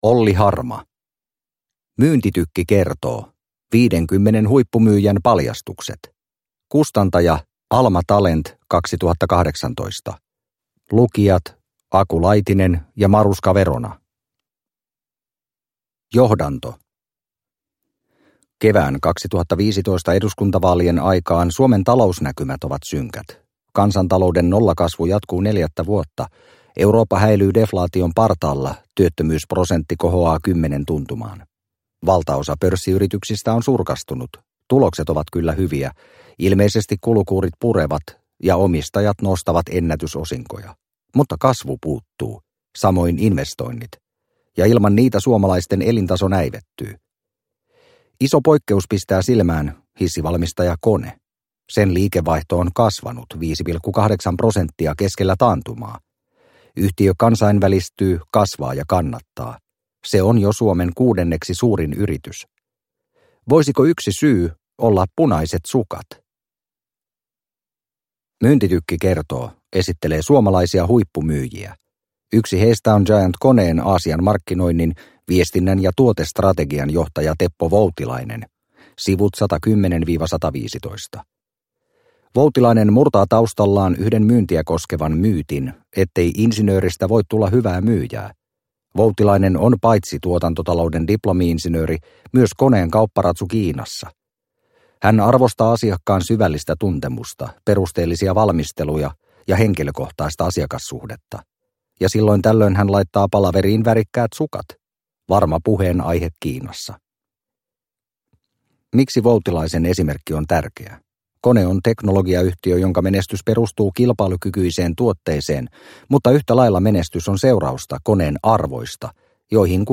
Myyntitykki kertoo – Ljudbok – Laddas ner